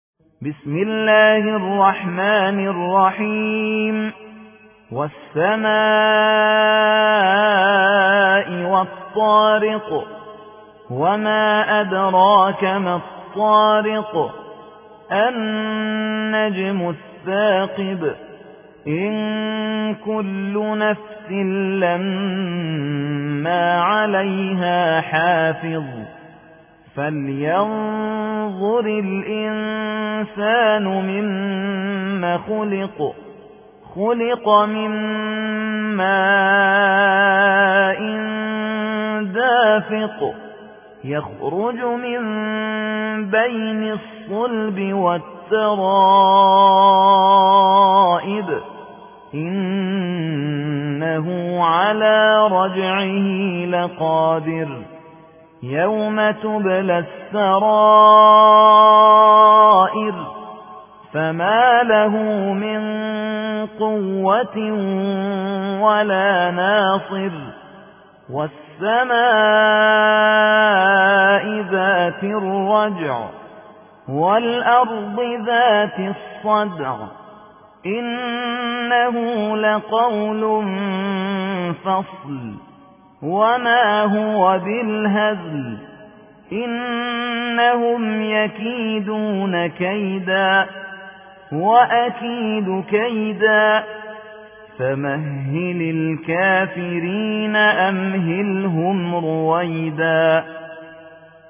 86. سورة الطارق / القارئ